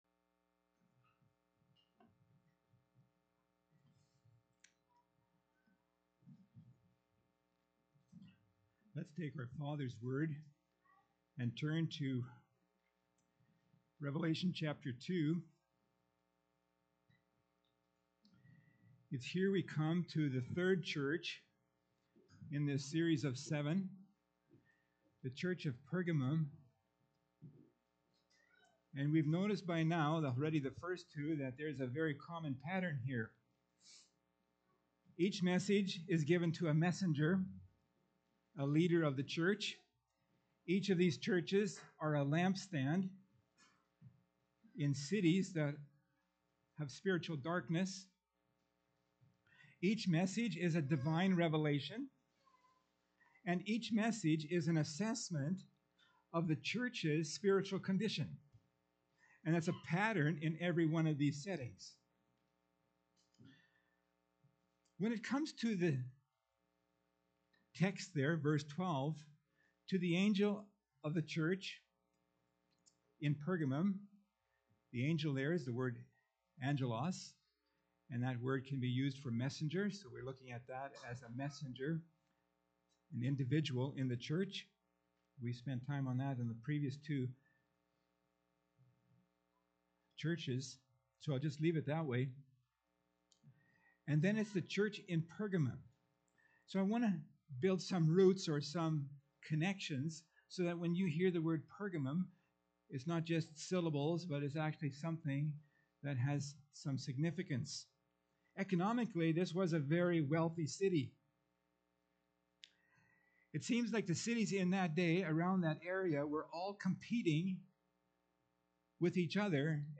Revelation Category: Pulpit Sermons Key Passage: Revalation 2:12-17 https